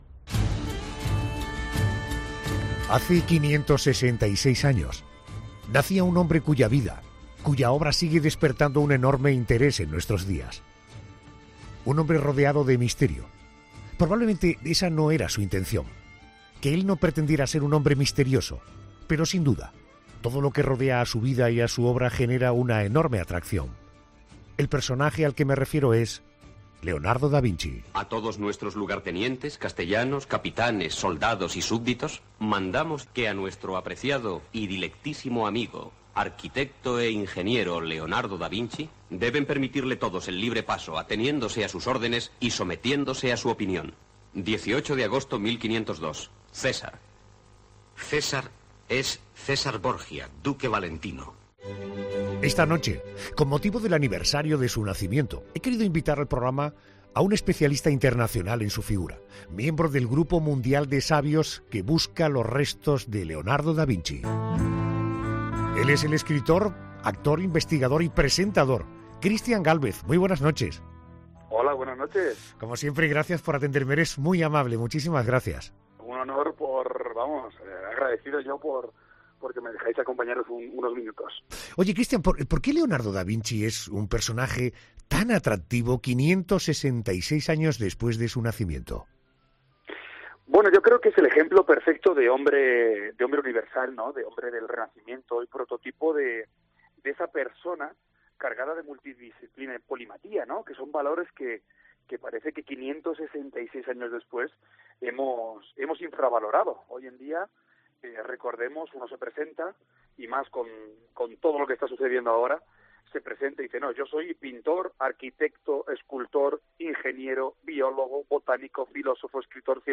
En 'La Noche de COPE' hablamos con el presentador, que es experto mundial en Da Vinci, cuando se cumplen 566 años de su nacimiento.